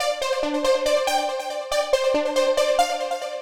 Index of /musicradar/future-rave-samples/140bpm